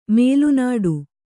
♪ mēlu nāḍu